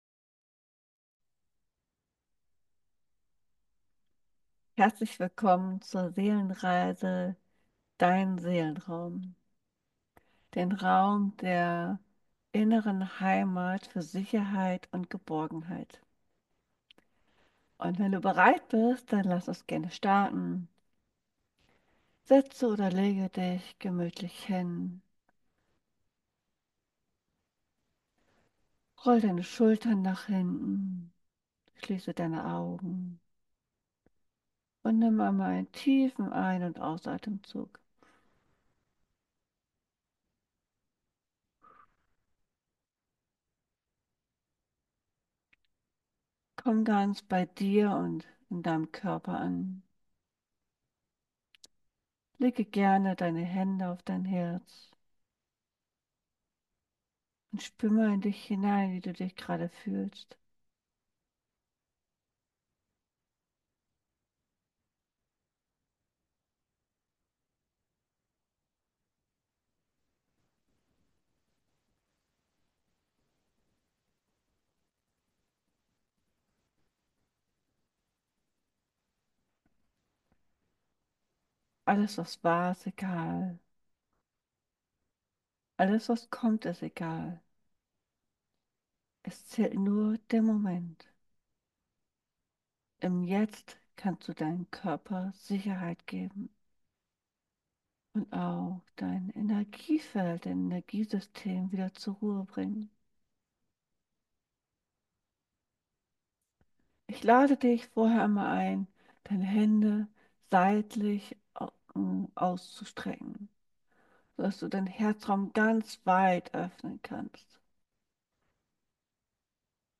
Schließe deine Augen und lass dich von der Musik und der sanften Stimme leiten, während wir uns...